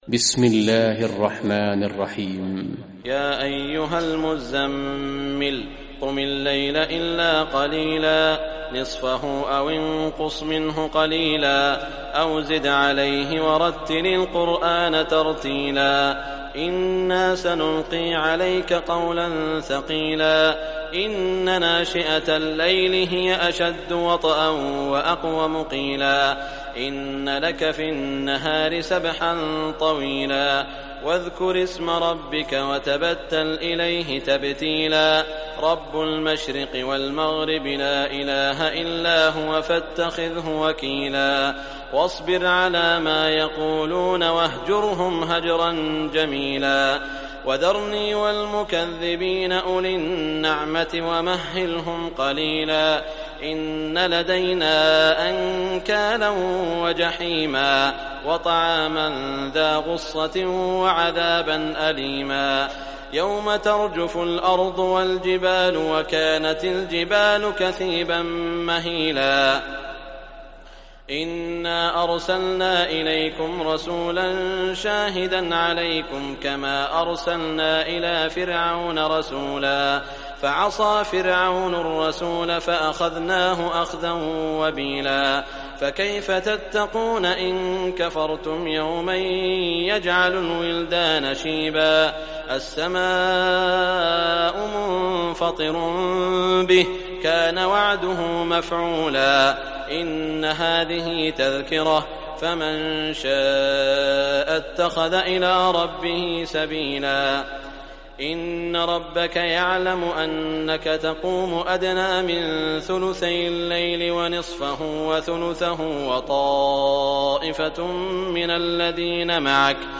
Surah Muzammil Recitation by Sheikh Shuraim
Surah Muzammil, listen or play online mp3 tilawat / recitation in Arabic in the beautiful voice of Sheikh Saud al Shuraim.